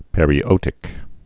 (pĕrē-ōtĭk)